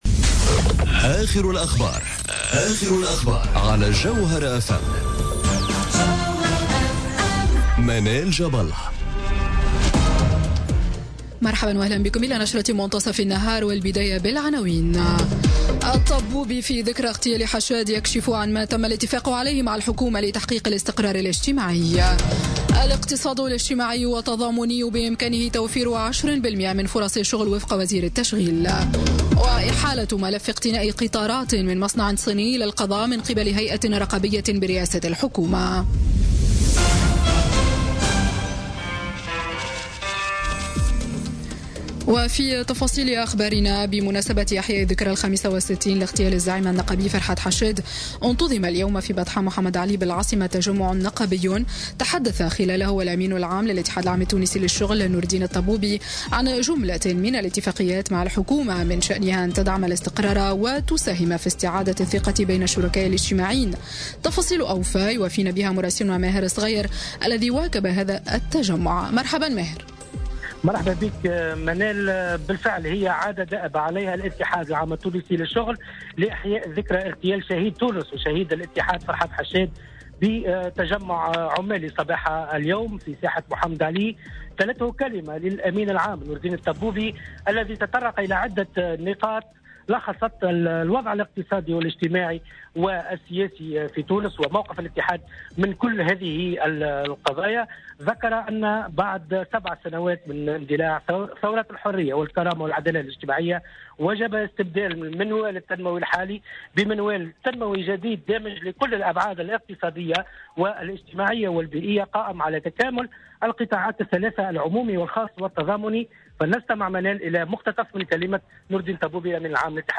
نشرة أخبار منتصف النهار ليوم الإثنين 4 ديسمبر 2017